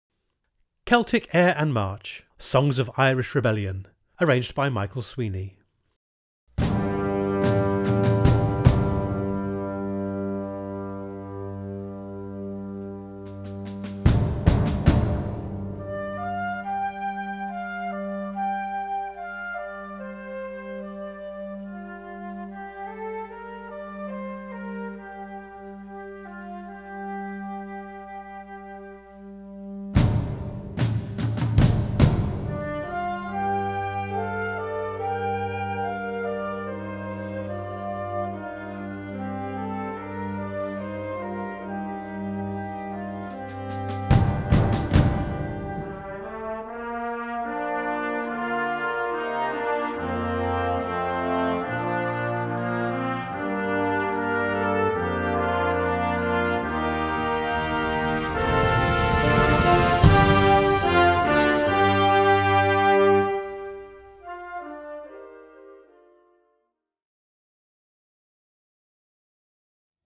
inspirational march